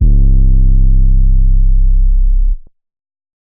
808_Oneshot_Red_C
808_Oneshot_Red_C.wav